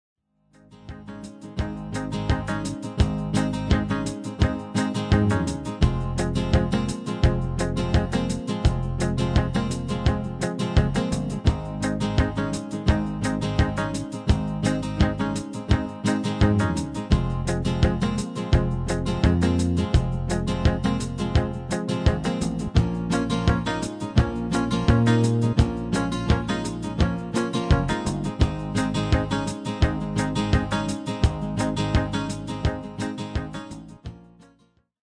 Demo/Koop midifile
Genre: Evergreens & oldies
- Vocal harmony tracks
Demo's zijn eigen opnames van onze digitale arrangementen.